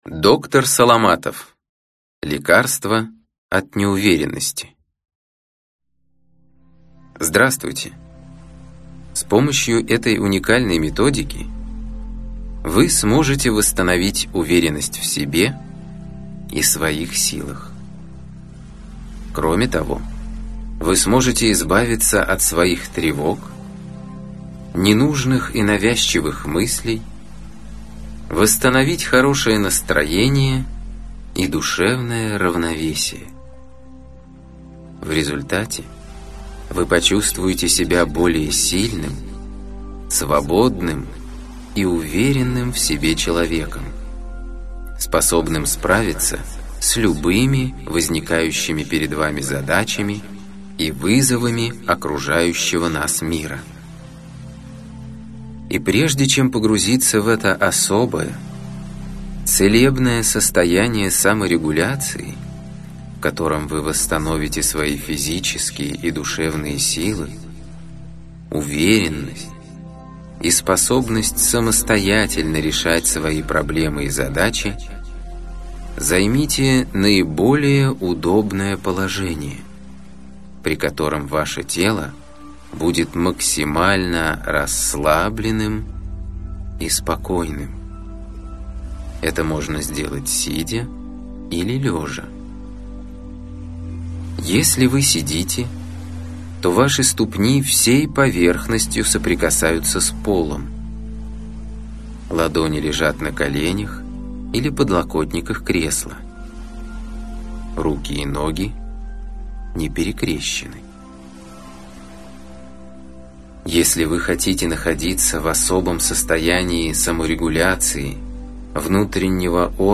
Аудиокнига Лекарство от неуверенности в себе | Библиотека аудиокниг